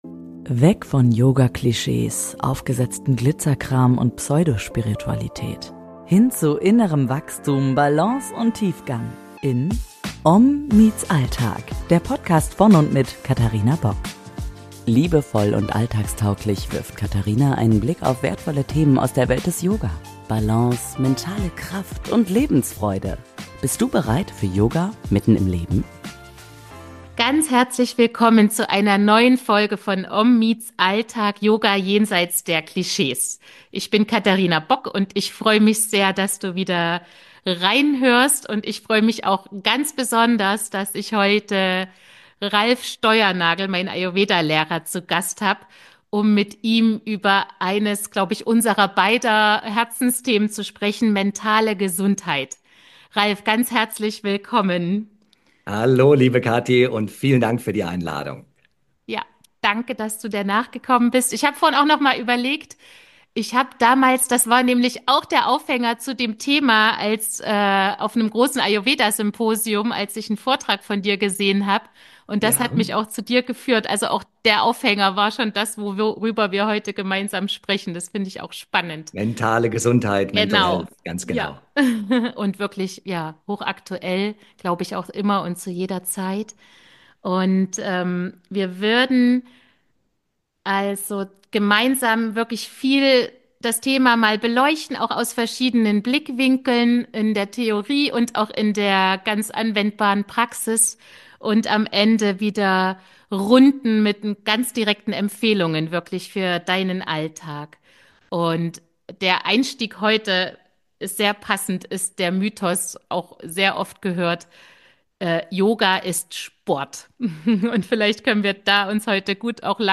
Gemeinsam nähern sie sich dem Thema aus verschiedenen Blickwinkeln – von der ayurvedischen Psychologie über die westliche Verhaltenstherapie bis hin zur buddhistischen Sichtweise. Was bedeutet mentale Gesundheit eigentlich wirklich? Und warum ist Yoga so viel mehr als nur Sport? Eine tiefgehende, praxisnahe und inspirierende Unterhaltung, die dir neue Perspektiven schenkt – für mehr Achtsamkeit, Mitgefühl und innere Balance im Alltag.